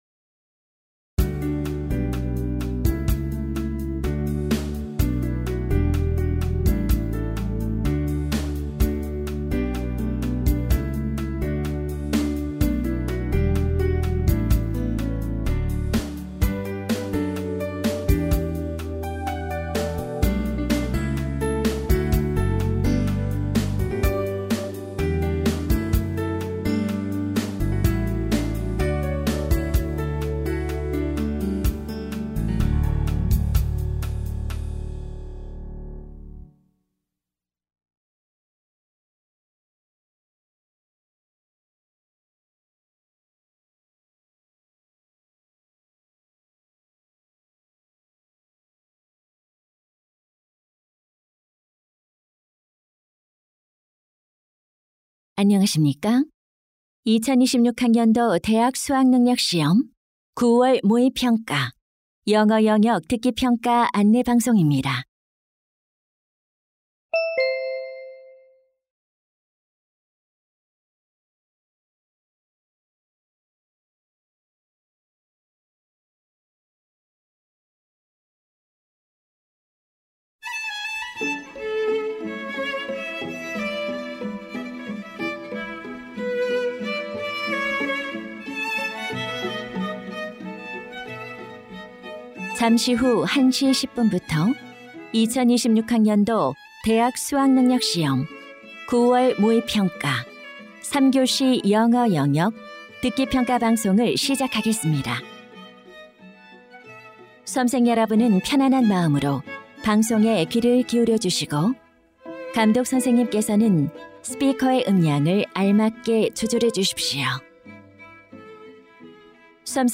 영어 듣기 파일